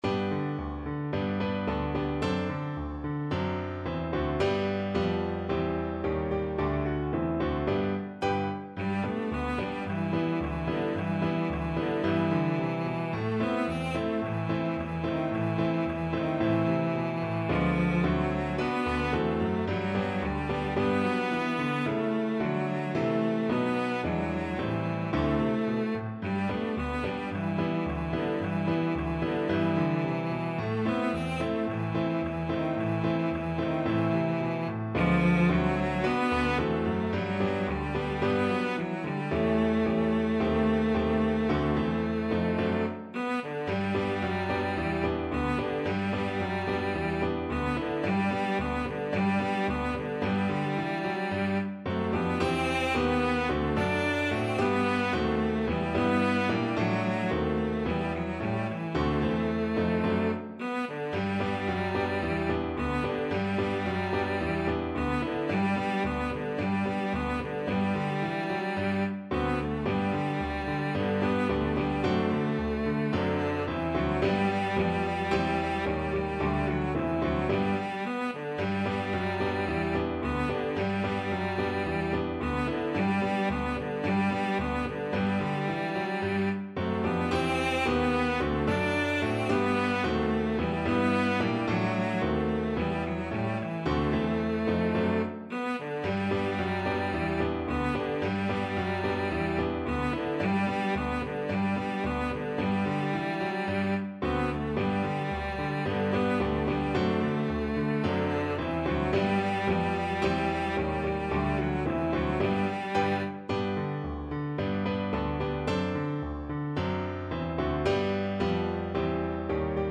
Cello version
2/4 (View more 2/4 Music)
Moderato allegro =110